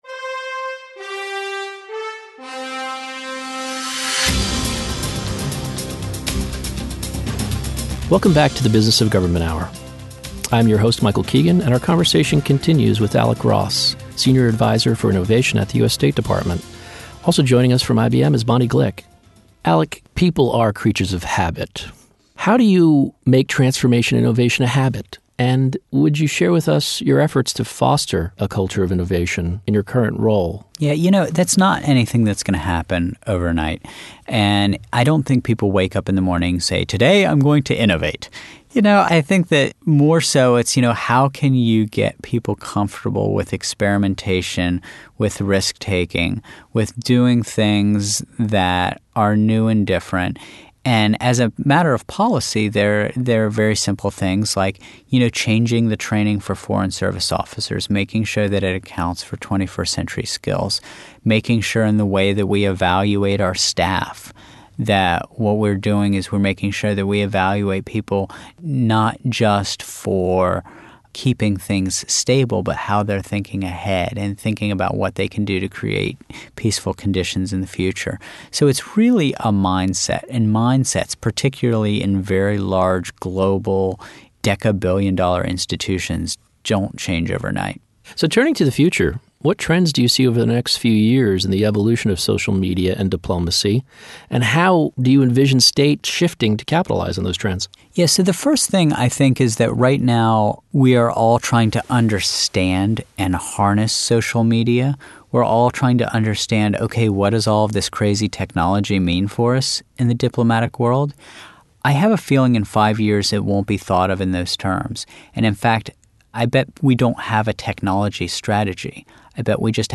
Interviews
The Business of Government Hour is a weekly conversation with government executives and thought leaders who are changing the way government does business.
Interviews are informative, insightful, and in-depth conversations on topics & trends at the intersection of government, technology, and leadership.